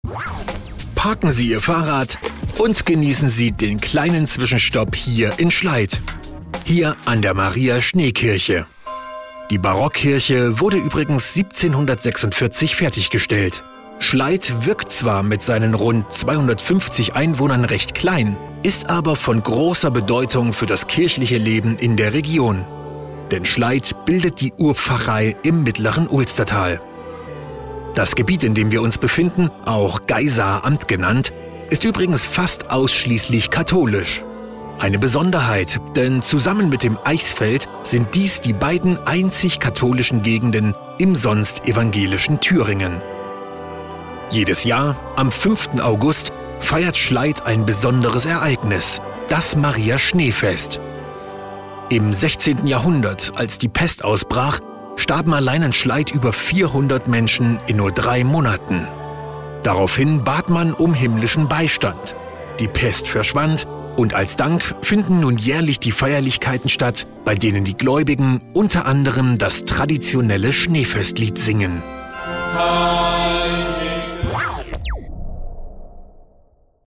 Sprechprobe: eLearning (Muttersprache):
german voice over artist